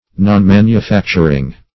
Nonmanufacturing \Non*man`u*fac"tur*ing\, a. Not carrying on manufactures.